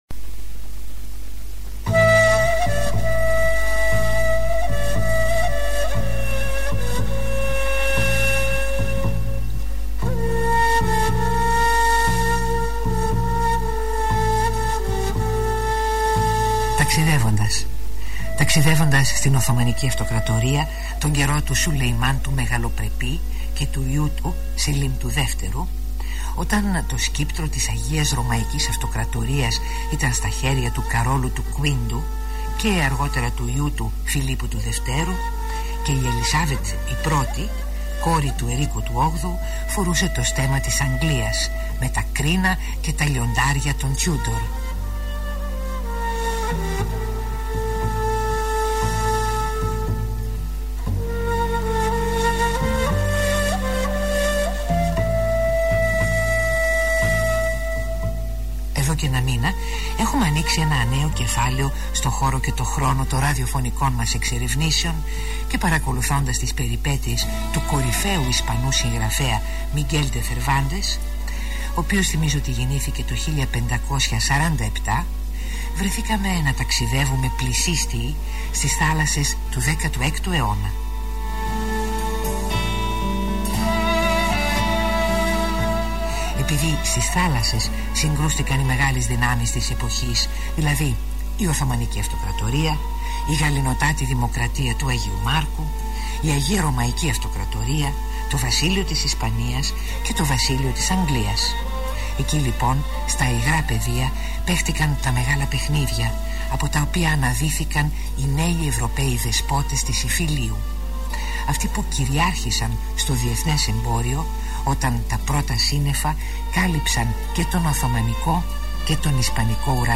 Ραδιοφωνική εκπομπή.
Η ποιότητα είναι πολύ καλή.